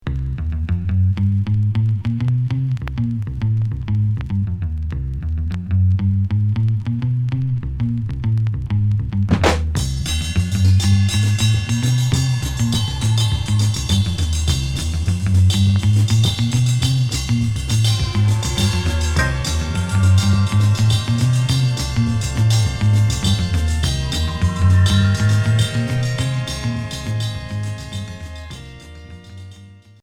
Groove psyché